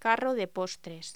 Locución: Carro de postres
voz